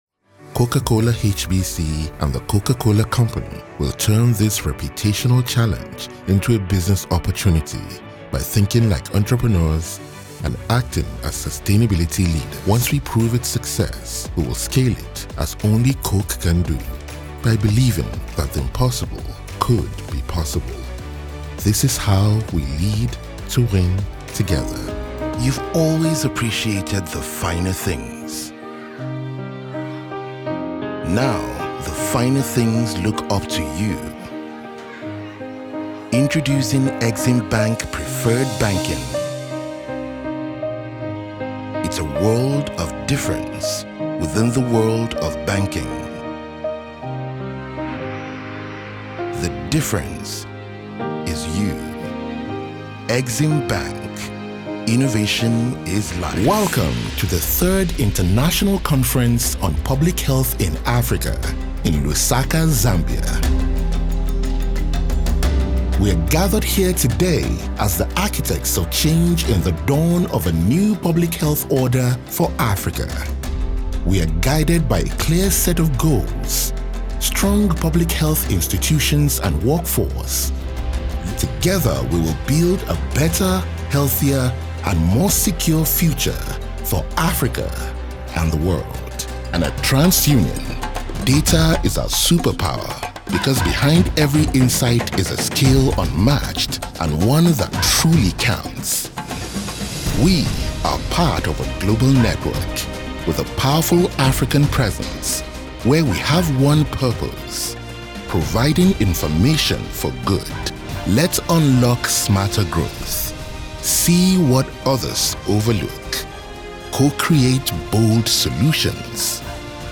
A quick selection of voice styles — from warm, conversational reads to grounded narration and confident commercial delivery.
Corporate & Brand Films
Professional voiceovers for internal comms, brand stories, product launches, and company profiles.
I provide Neutral English, authentic African accent English, including Nigerian, West African, East African, South African, and neutral Pan-African delivery — natural, light or exaggerated accent.